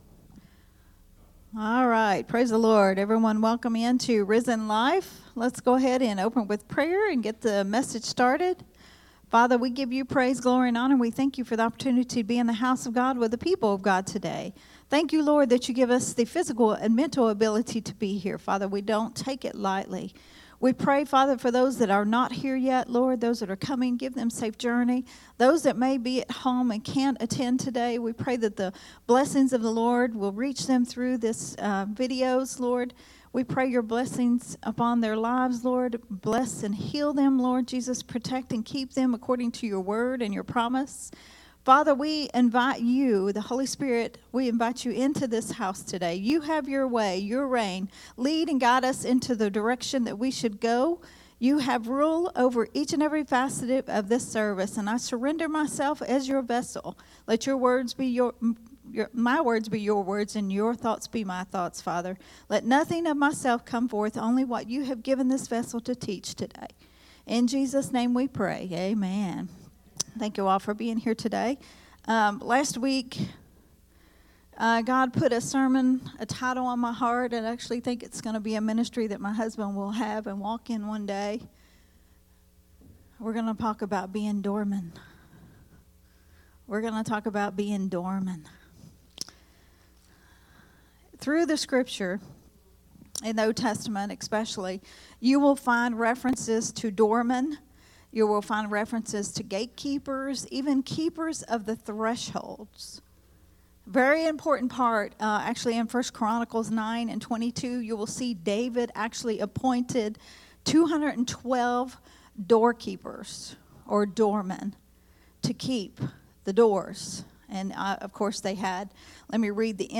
a Sunday Morning Risen Life teaching
recorded at Growth Temple Ministries